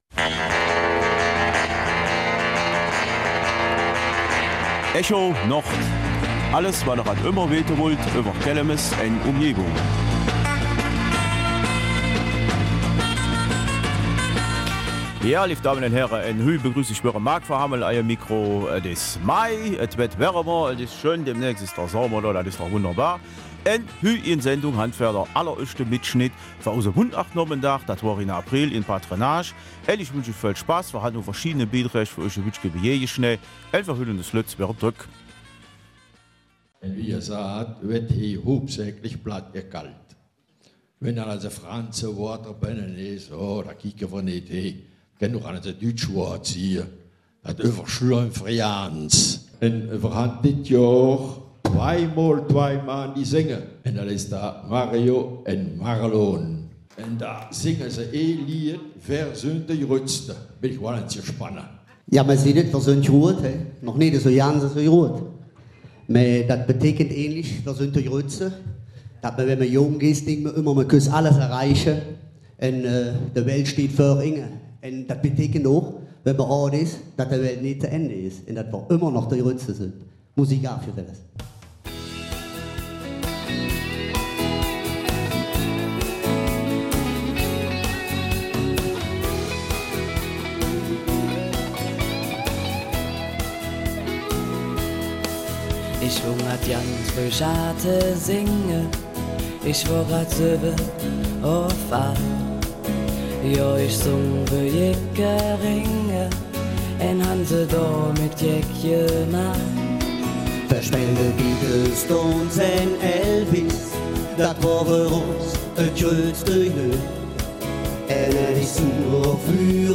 Am 14. April fand der diesjährige Mundartnachmittag in der Kelmiser Patronage statt. Organisiert durch den Seniorenbeirat Kelmis unter der Schirmherrschaft der Sozialschöffin mit Unterstützung des BRF wurden viele Amaröllchen, Geschichten und plattdeutsches Liedgut aus der Region geboten.
Die ersten mitgeschnittenen Beiträge des Nachmittags werden diesen Sonntag ausgestrahlt.